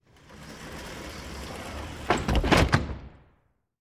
Звуки раздвижных дверей
Звук раздвигающейся двери с тепловыми датчиками у входа в магазин